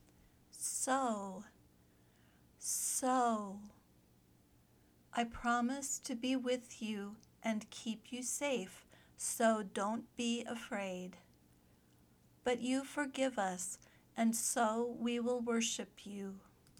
/səʊ/ (adverb)